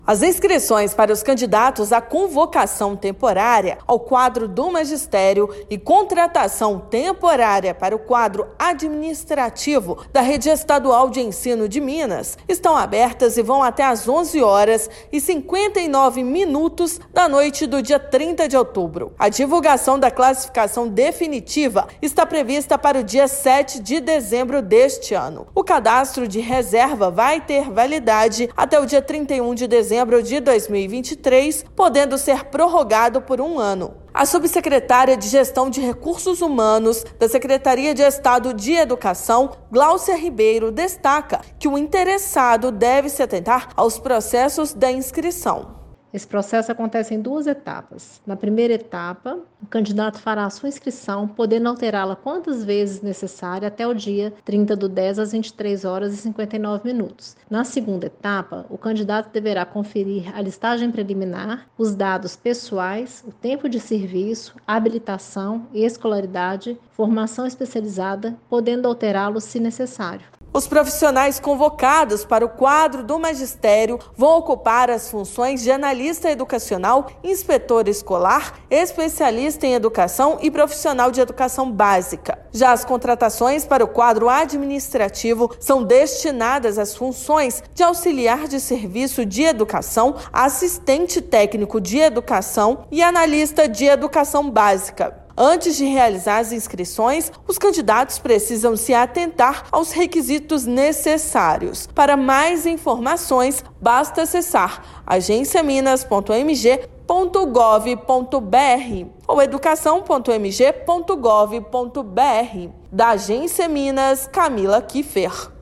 Candidato pode se inscrever de 21 a 30/10 pelo sistema on-line, que já está disponível no portal da Educação. Ouça matéria de rádio.